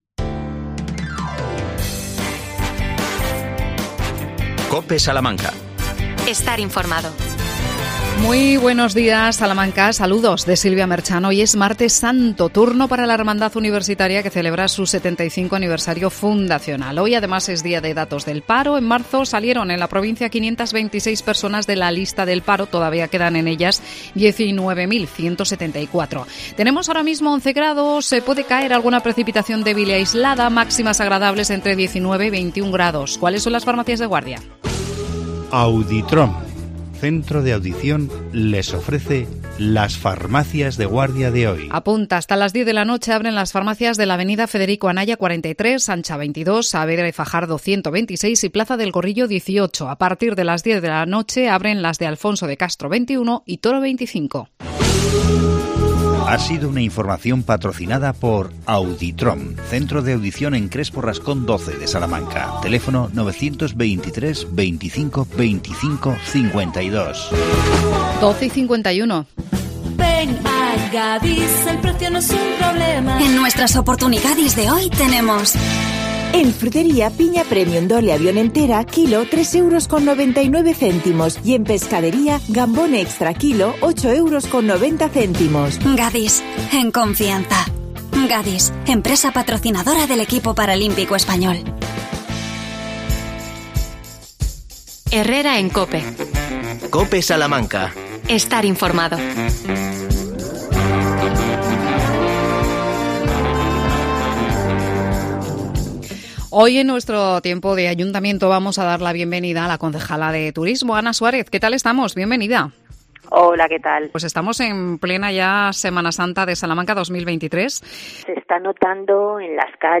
AUDIO: Turismo en Salamanca en Semana Santa. Entrevistamos a la concejala Ana Suárez.